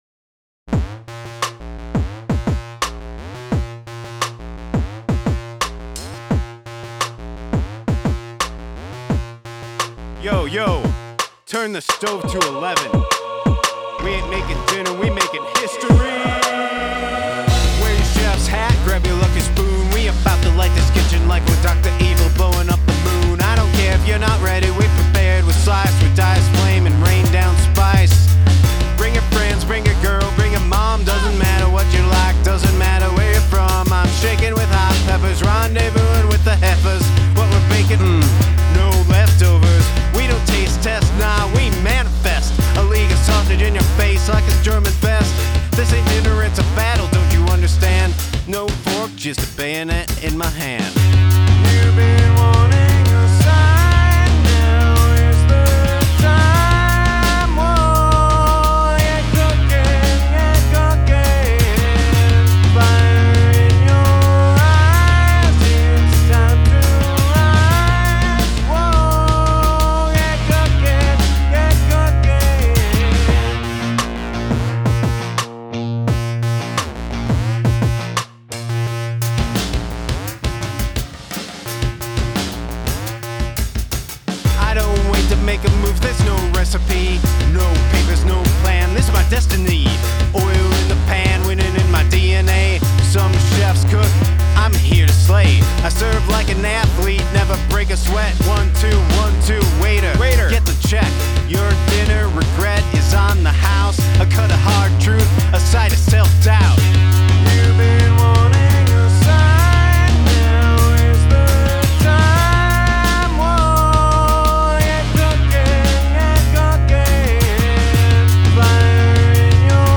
Great vocal. It cuts through. Good arrangement.